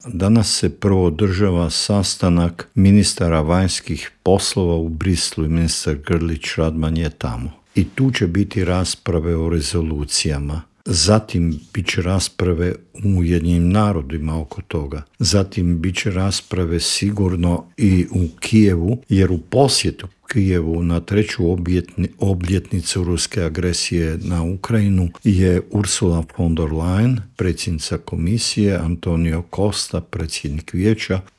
O tome se proteklih dana razgovaralo i u Vladi, a posebni savjetnik premijera Andreja Plenkovića i bivši ministar vanjskih i europskih poslova Mate Granić u Intervjuu Media servisa rekao je da je Ured predsjednika informiran o svemu što radi Vlada RH.